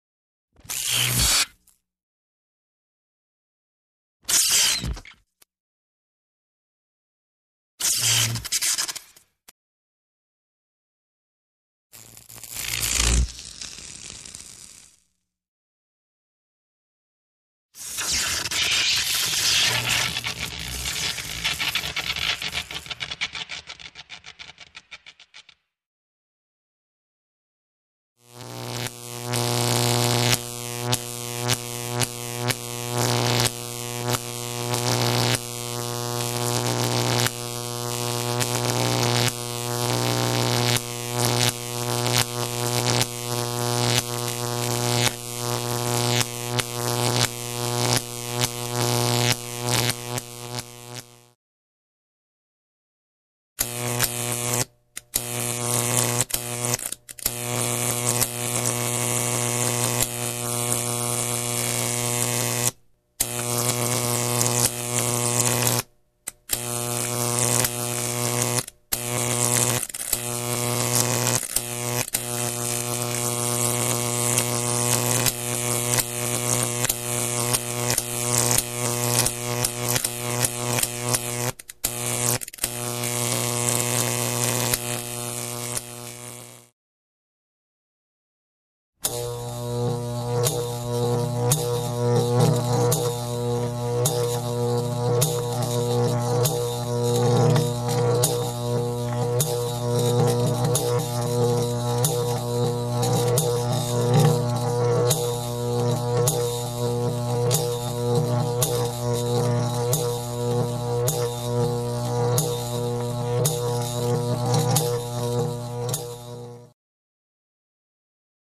Звуки отключения электричества
Звук высоковольтного разряда продолжительное искрение и дуга